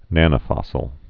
(nănə-fŏsəl)